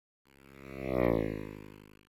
The following is a doppler - shifted bassoon note, traveling in front of the listener
Bassoon travelling past listener
bassoon.straight.wav